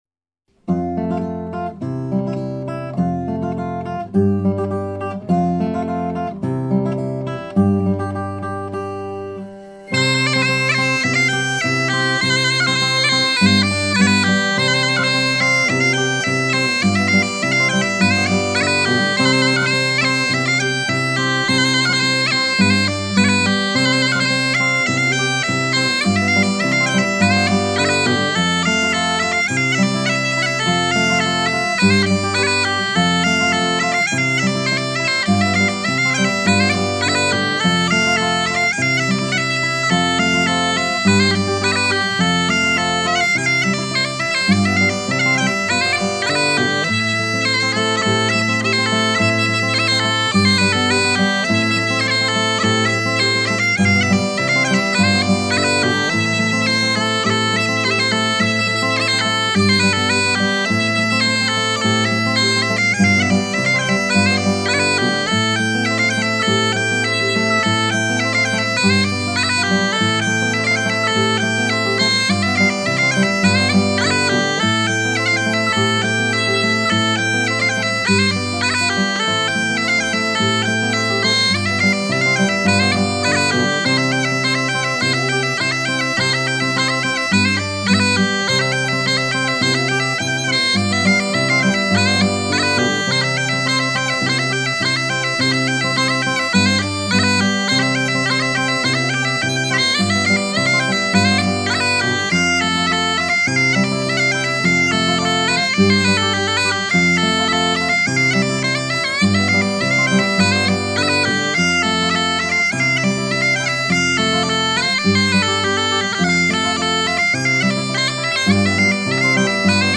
zf_The_lads_makes_yours_tails_toodle_guitare.mp3